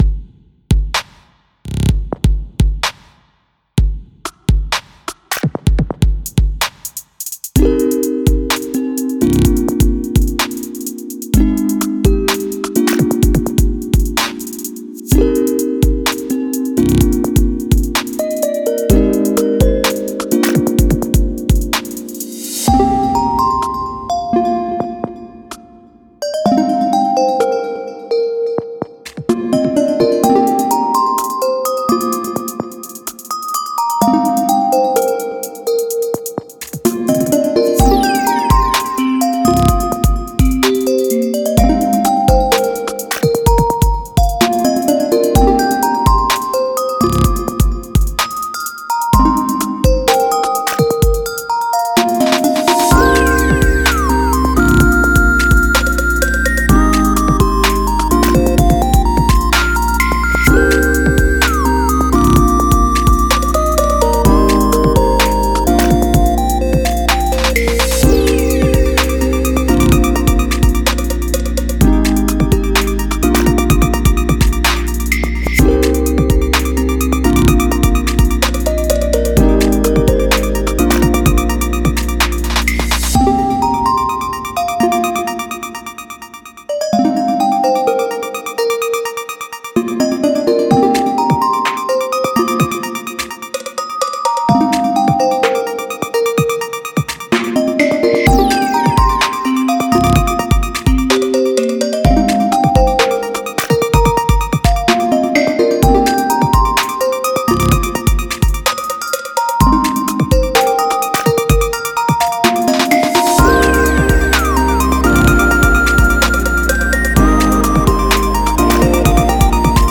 - "Frozen Mist (WIP)" - I dig the music box sounds, but I wasn't really a fan of the percussion, particularly the kick.